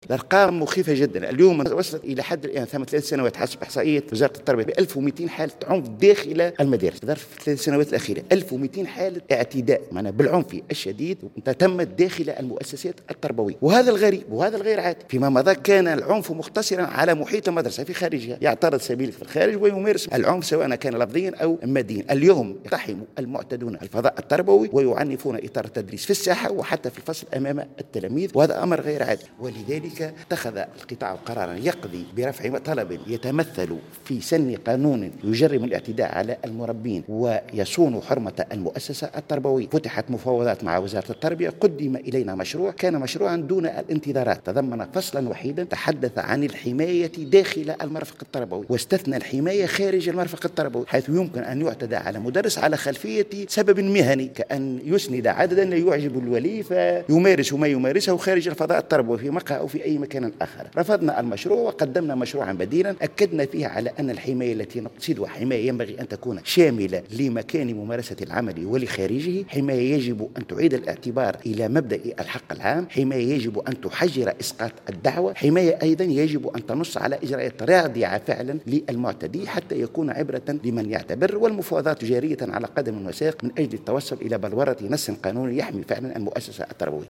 وأضاف في تصريح لمراسل الجوهرة اف ام، أن هذه الأرقام تعتبر مخيفة وتدل على انتشار العنف اللفظي والبدني ضد الإطار التربوي بشكل كبير.